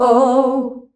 OUUH  C.wav